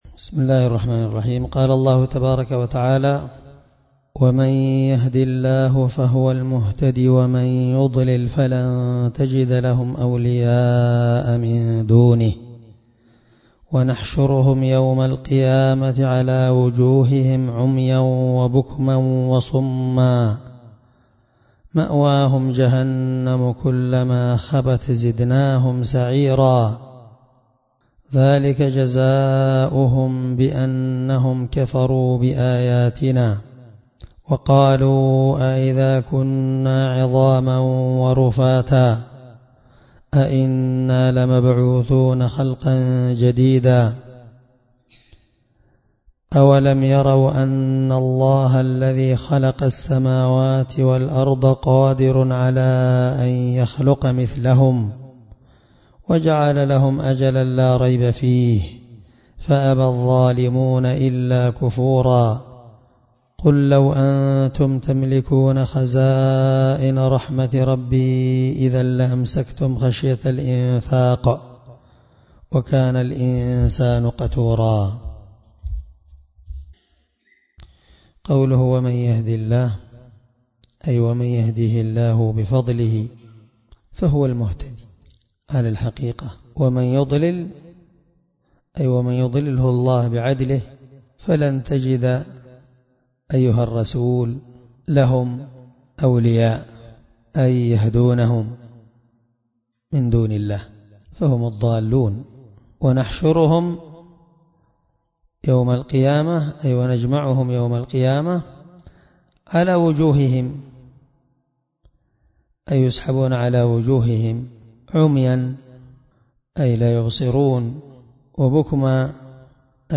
مع قراءة لتفسير السعدي